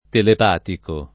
[ telep # tiko ]